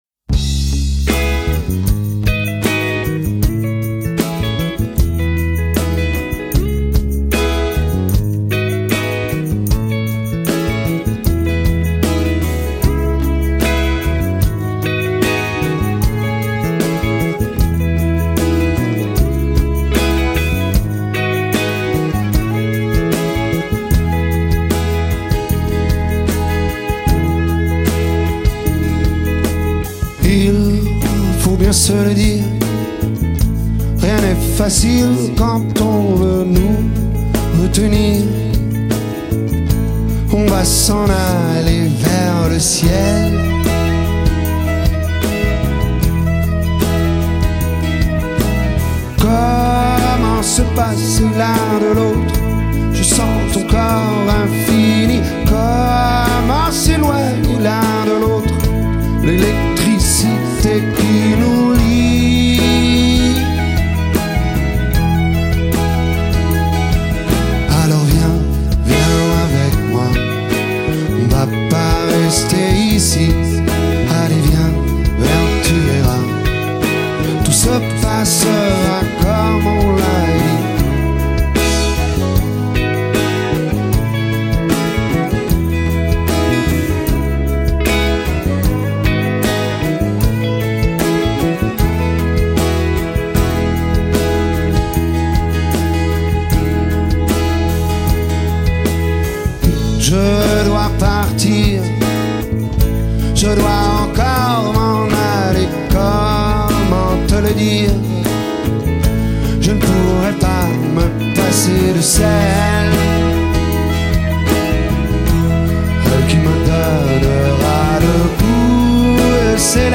vocal guitare
batterie
basse
guitare solo
violon.